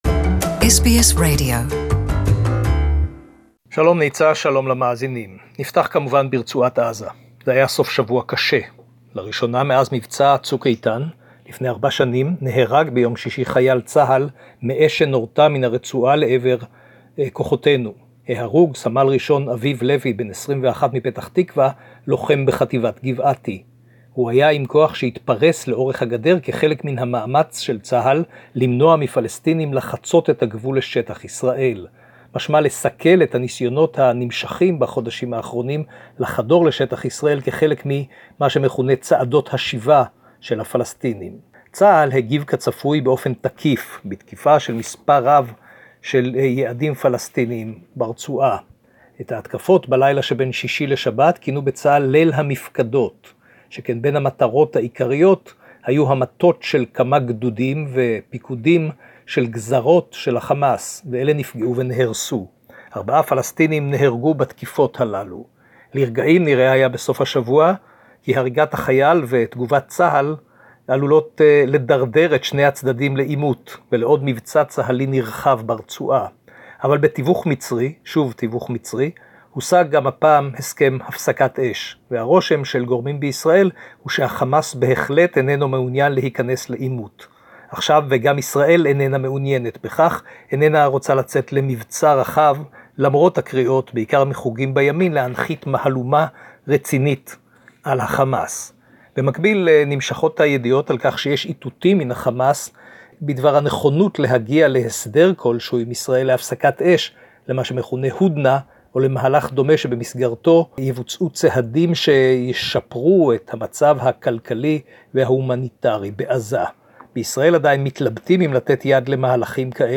reporting to SBS Radio Australia